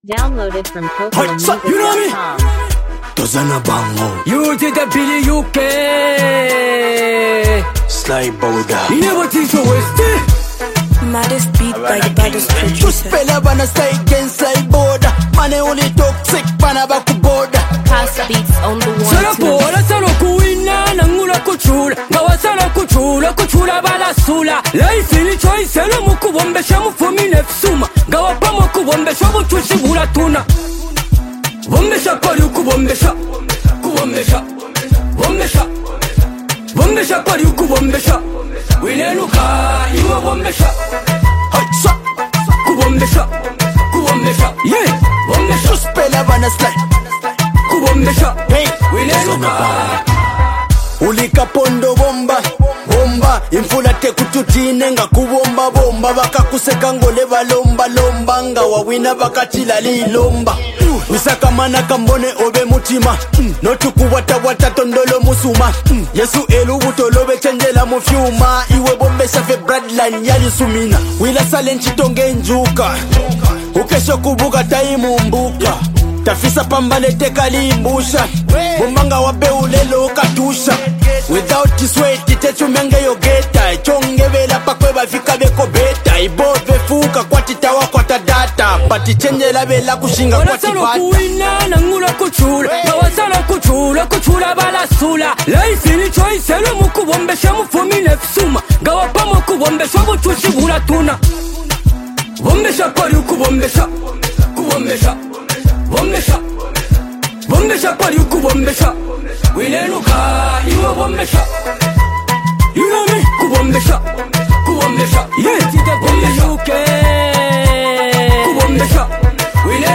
while his cadence rides smoothly over the hard-hitting beat.
a gritty verse that adds weight and street credibility
Zambian urban music scene